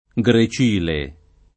vai all'elenco alfabetico delle voci ingrandisci il carattere 100% rimpicciolisci il carattere stampa invia tramite posta elettronica codividi su Facebook grecile [ g re ©& le ] o gricile [ g ri ©& le ] s. m. — voce roman. per «ventriglio»